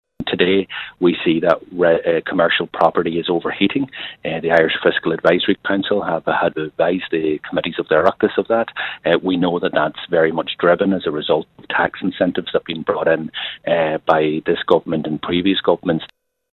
Sinn Féin Finance spokesperson and Donegal Deputy Pearse Doherty says some lessons haven’t been learned since the last property crash: